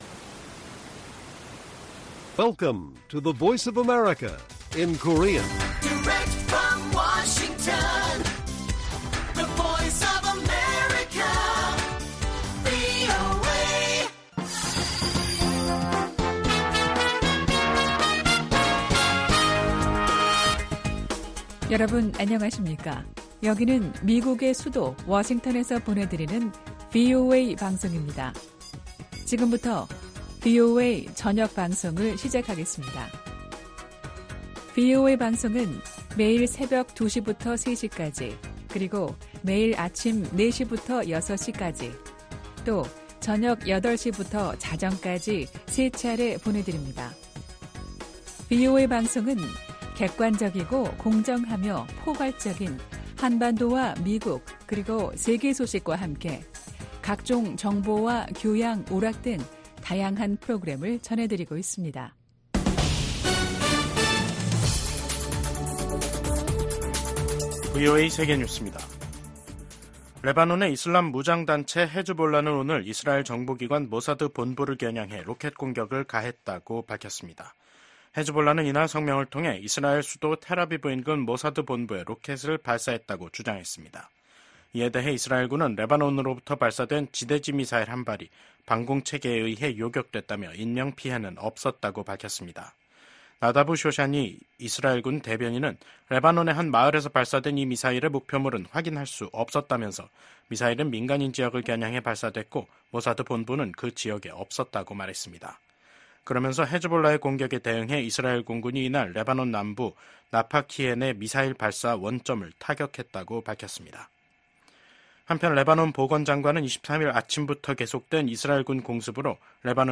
VOA 한국어 간판 뉴스 프로그램 '뉴스 투데이', 2024년 9월 25일 1부 방송입니다. 조 바이든 미국 대통령이 임기 마지막 유엔총회 연설에서 각국이 단합해 전 세계가 직면한 어려움들을 이겨내야 한다고 밝혔습니다. 토니 블링컨 미국 국무장관은 심화되는 북러 군사협력을 강하게 규탄하며 국제사회의 강력한 대응의 필요성을 역설했습니다.